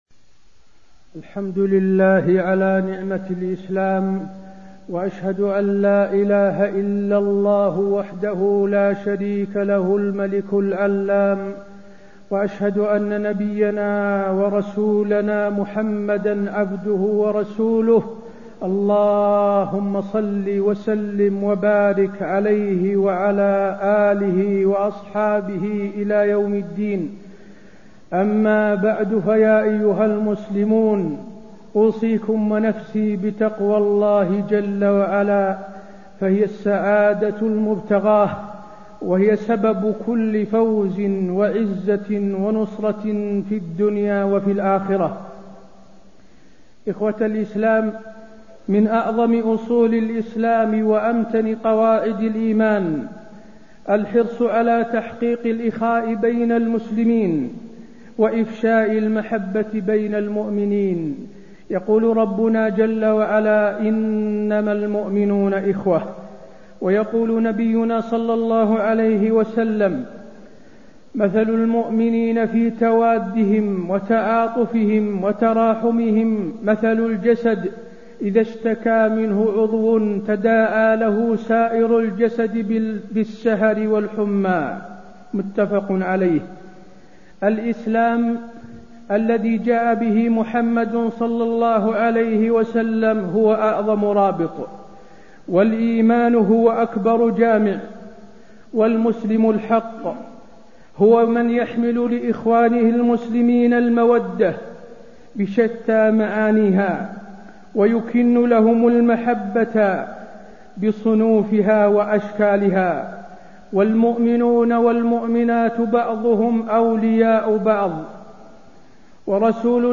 تاريخ النشر ٢٣ شعبان ١٤٣٣ هـ المكان: المسجد النبوي الشيخ: فضيلة الشيخ د. حسين بن عبدالعزيز آل الشيخ فضيلة الشيخ د. حسين بن عبدالعزيز آل الشيخ أهمية الأخوة الإسلامية